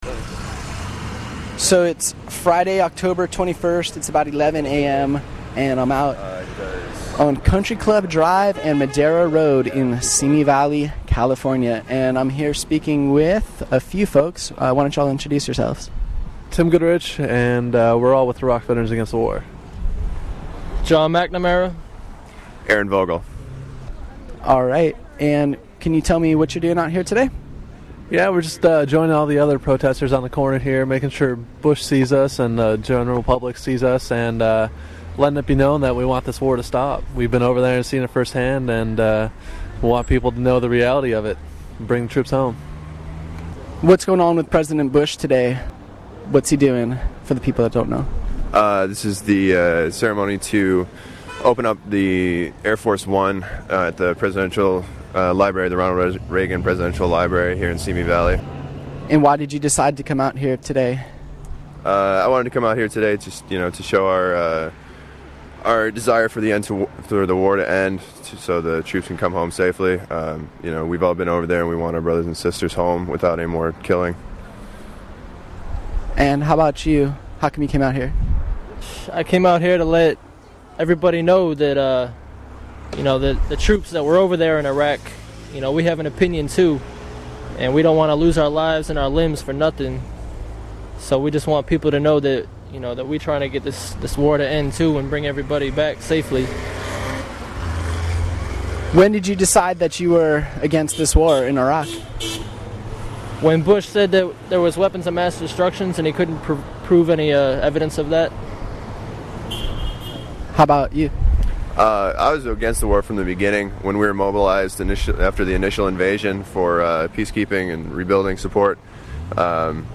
Most of the people were holding signs calling for peace or denouncing Bush and his administration in one way or another.